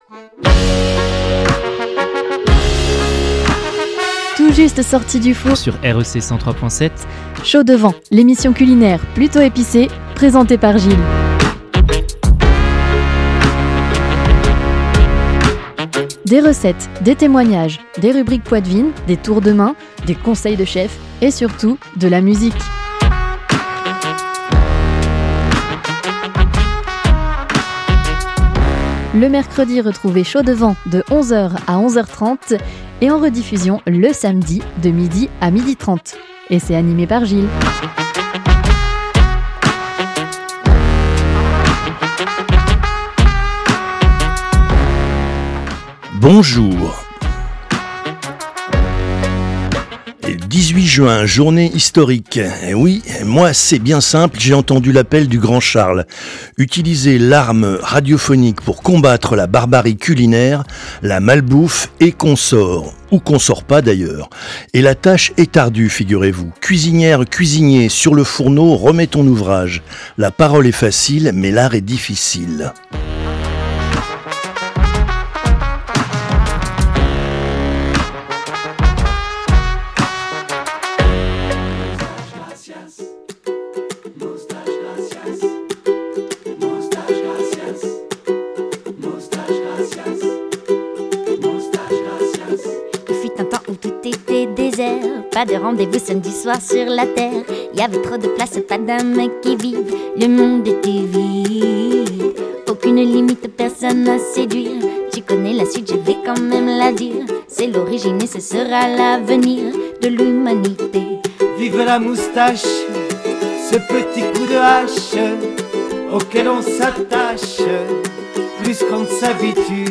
avec anecdotes, témoignages , rubriques , recettes , conseils de chef et forcément de la musique !